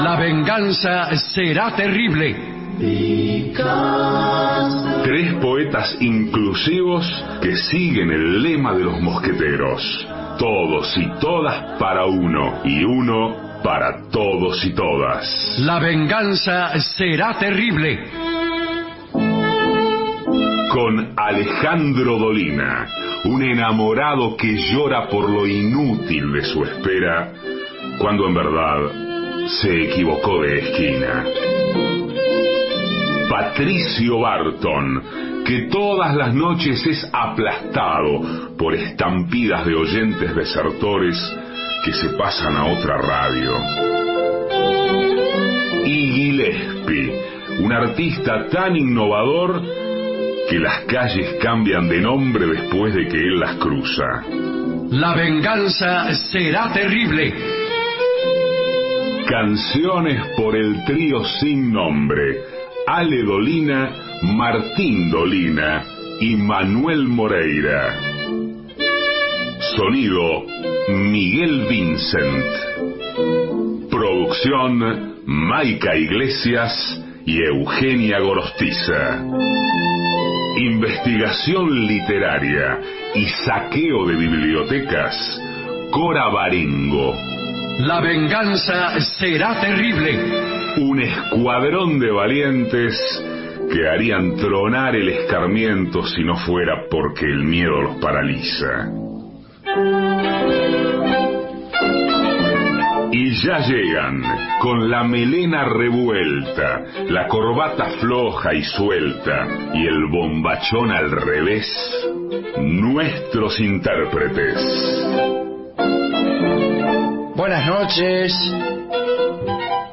(Canta El Trío Sin Nombre)